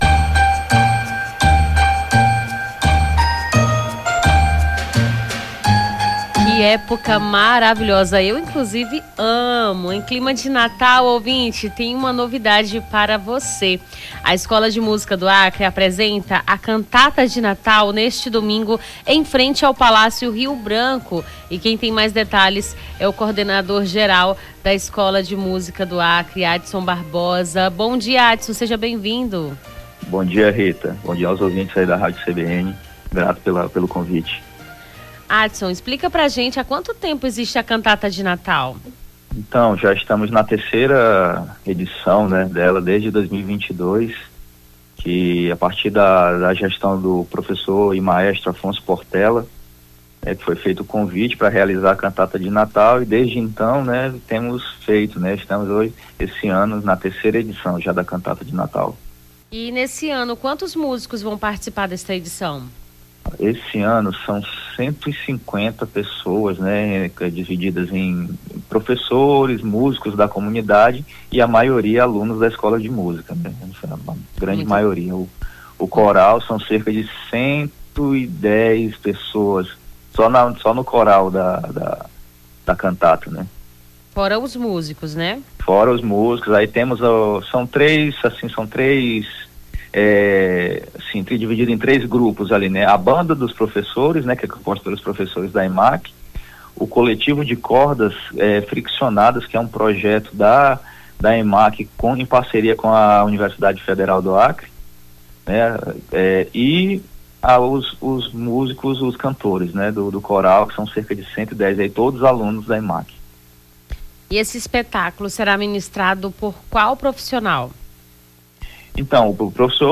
Nome do Artista - CENSURA - ENTREVISTA CANTATA DE NATAL (13-12-24).mp3